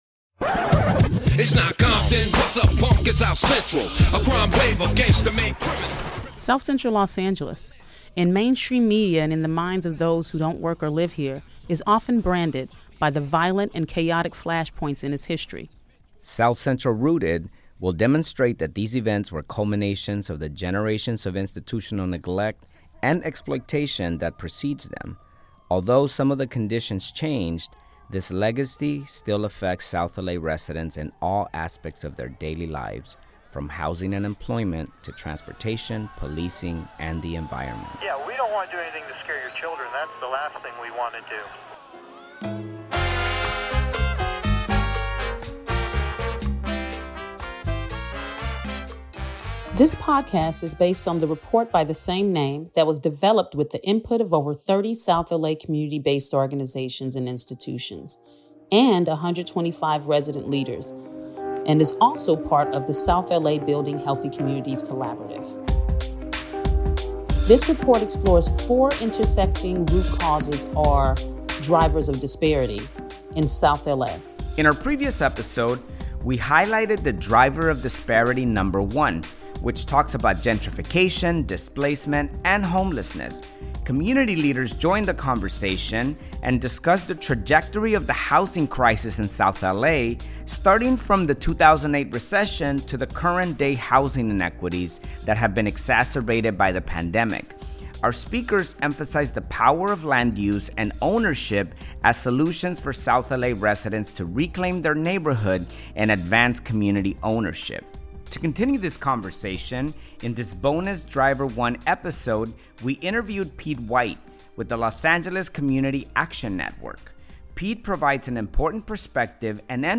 This bonus episode on Driver 1: Gentrification, Homelessness and Displacement features a candid conversation